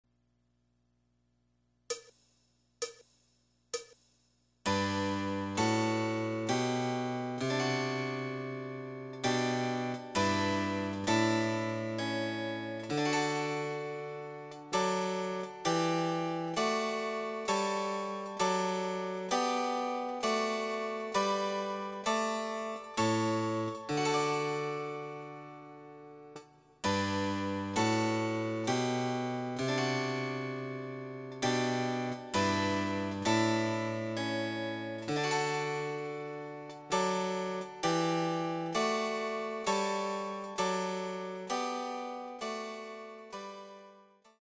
★フルートの名曲をチェンバロ伴奏つきで演奏できる、「チェンバロ伴奏ＣＤつき楽譜」です。
試聴ファイル（伴奏）
第３番　ト長調
デジタルサンプリング音源使用
※フルート奏者による演奏例は収録されていません。